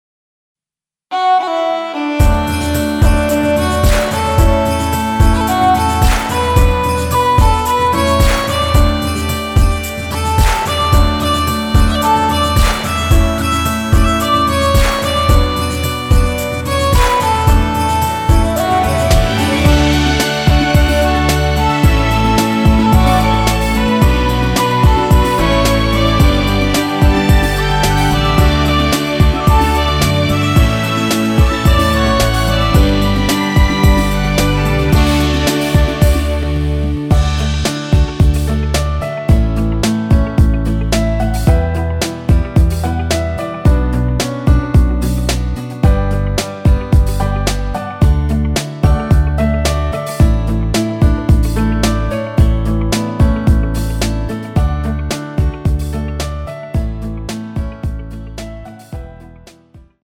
엔딩이 페이드 아웃이라노래 부르시기 편하게
원곡에서 4분 45초까지하고 엔딩을만들어 놓았습니다.(멜로디 MR 미리듣기 확인)
앞부분30초, 뒷부분30초씩 편집해서 올려 드리고 있습니다.
중간에 음이 끈어지고 다시 나오는 이유는